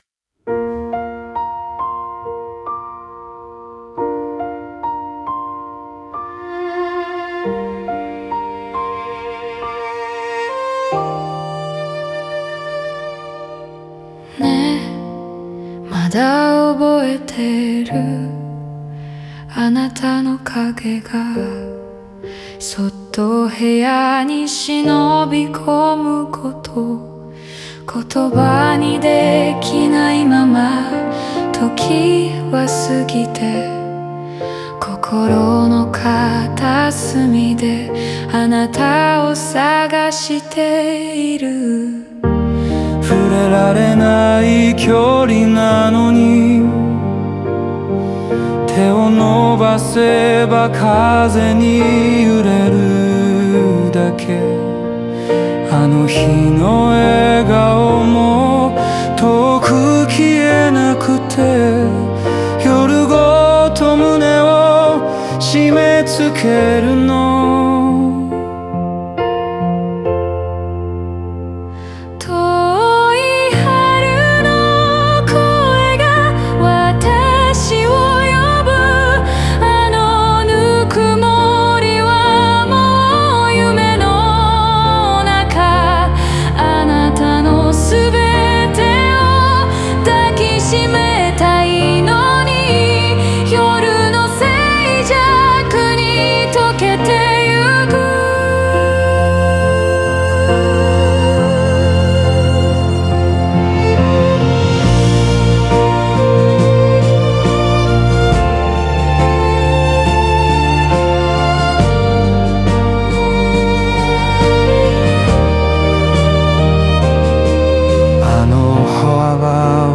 オリジナル曲♪
間奏やアウトロでは、ギターとバイオリンの余韻が心情をさらに引き立て、夜の静寂に溶けるような儚さを演出。